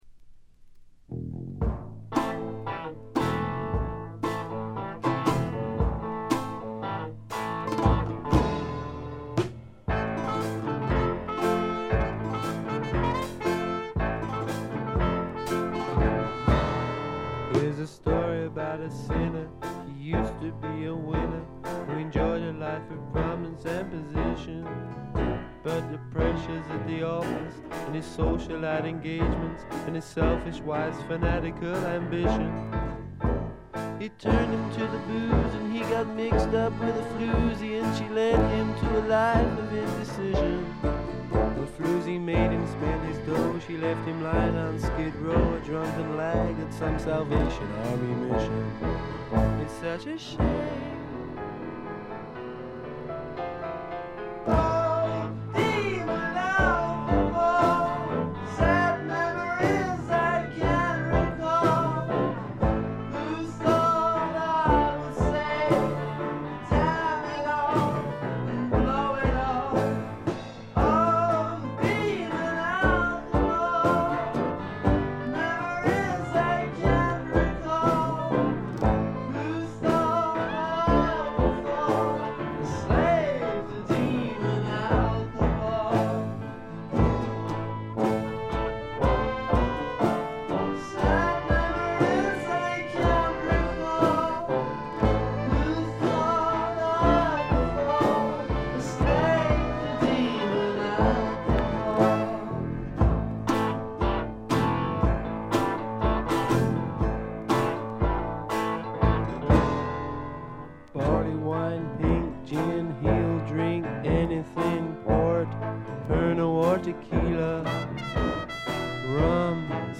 A面冒頭チリプチ、A5エンディングでチリプチ。
試聴曲は現品からの取り込み音源です。